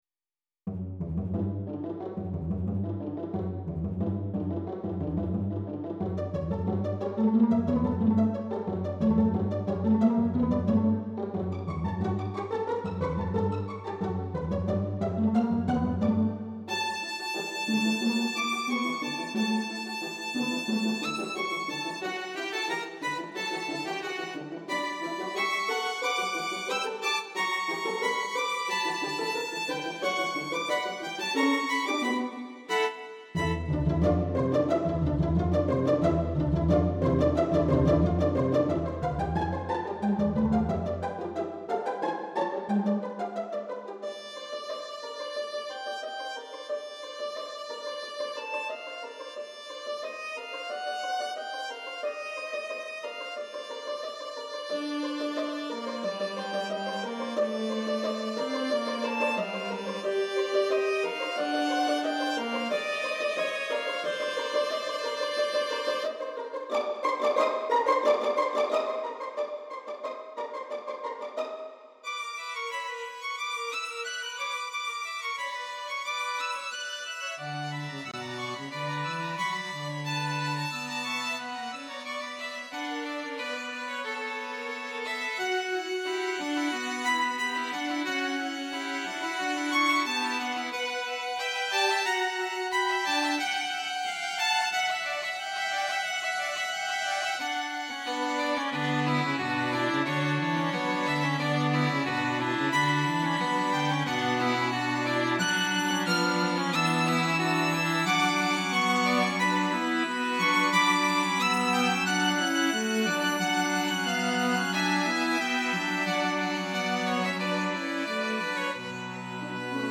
The following are are some of my compositions realized in midi.
A tonal string quartet study based on fragments of themes by composer and founder of The Boston Conservatory, Julius Eichberg: